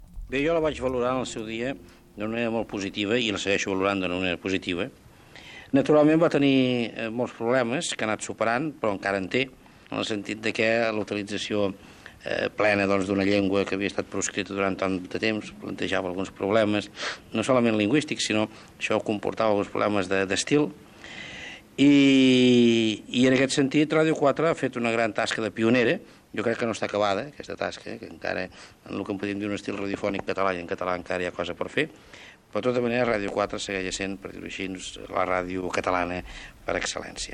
Paraules del president de la Generalitat Jordi Pujol sobre el paper de Ràdio 4 en el cinquè aniversari de l'emissora
Extret del programa Directe 4.0 emès el 13 de desembre de 2011 per Ràdio 4